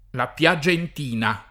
la pLaJent&na] top. (a Firenze) — da una variante d’origine non chiara il nome della scuola di Pergentina (oltreché di Piagentina), di pittori (macchiaioli) ivi operanti dopo la metà dell’800